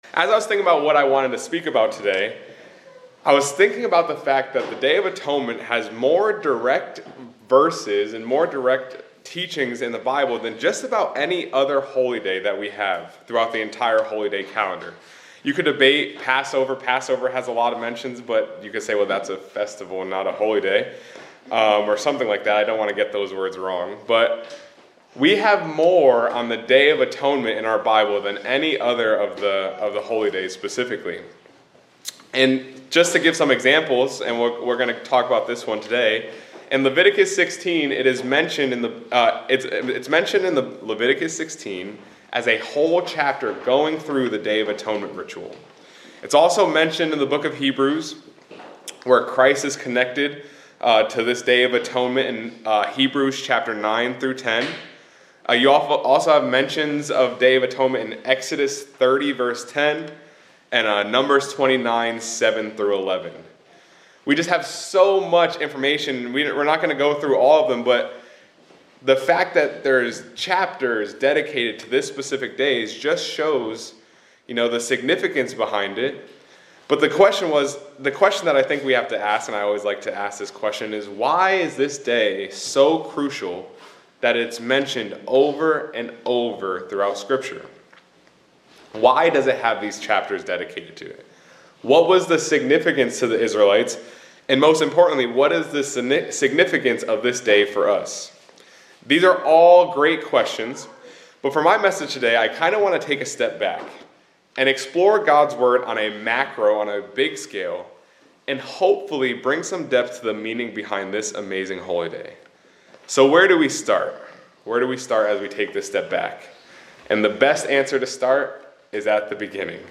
This sermonette focuses on the Day of Atonement and its central significance in the Bible, especially as described in the book of Leviticus.